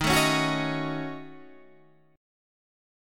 D#m7b5 chord